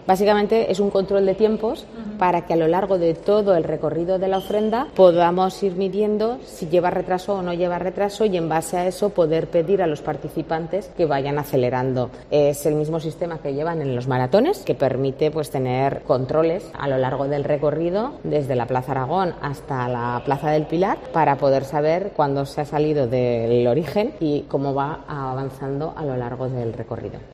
La alcaldesa explica el funcionamiento del chip